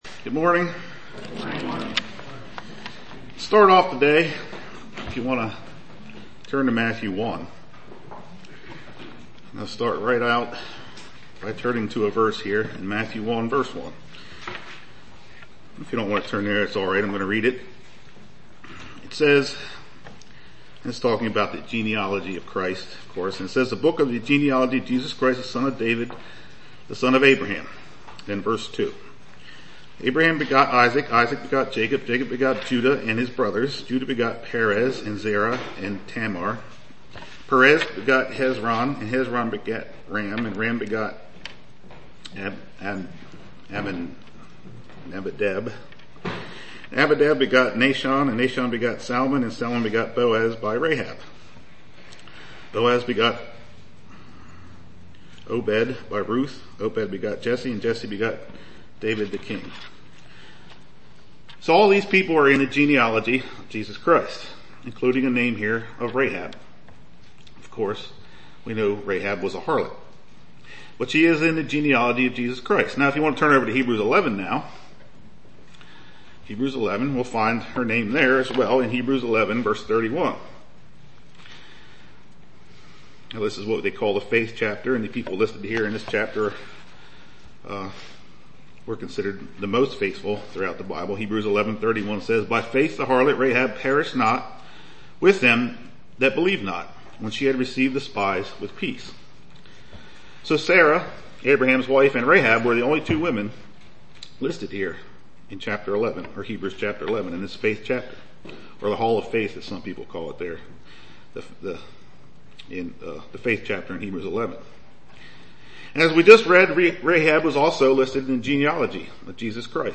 Sermon
Given in Lewistown, PA York, PA